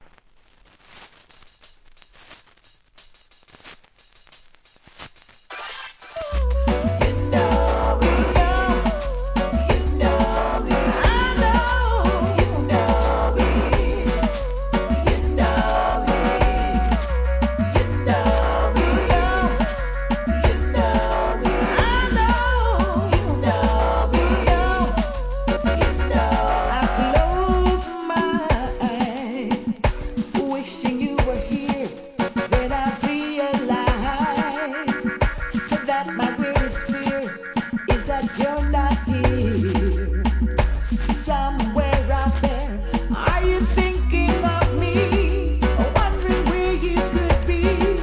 REGGAE
ベテランシンガーによるラヴァーズチューン